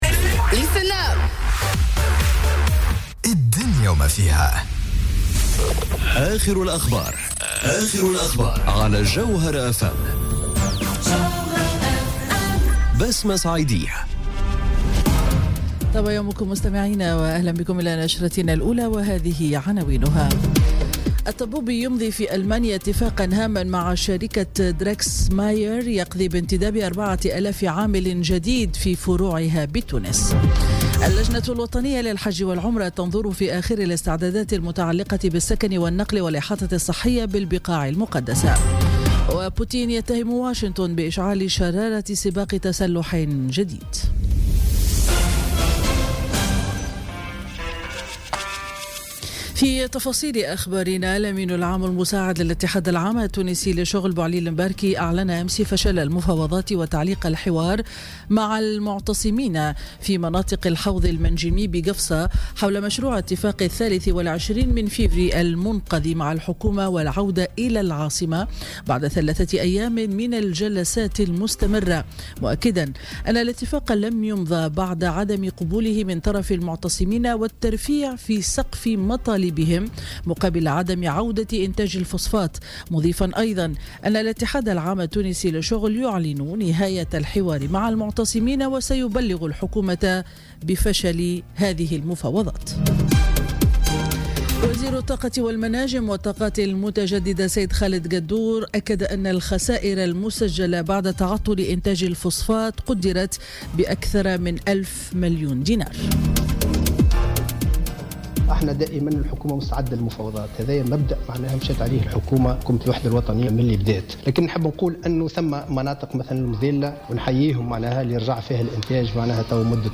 نشرة أخبار السابعة صباحا ليوم الجمعة 2 مارس 2018